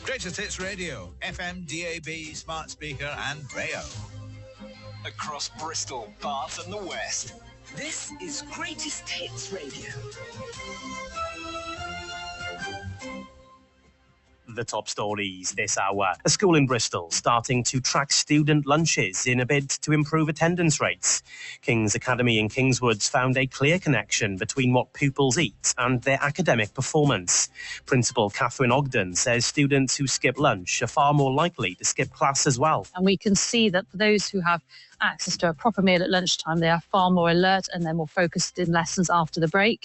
Greatest Hits Radio recently ran a series of stories across its news network focusing on school dinners and the importance of healthy, balanced meals in education.